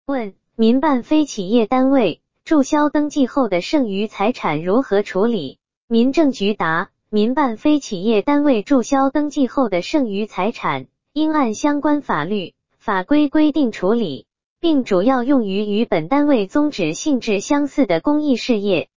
语音播报
语音合成中，请耐心等待...